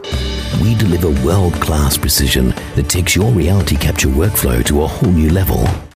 E-learning
Neumann TLM 103 mic